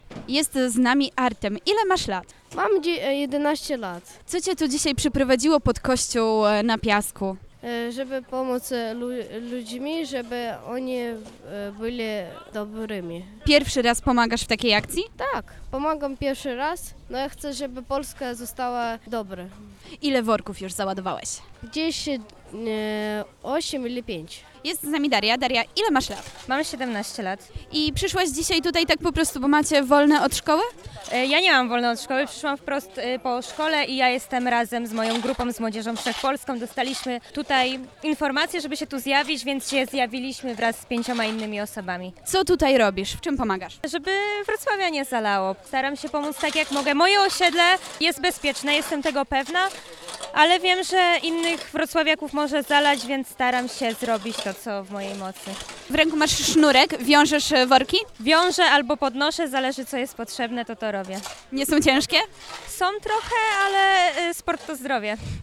Nasza reporterka również z nimi porozmawiała.
na-strone_3_mlodziez-i-dzieci.mp3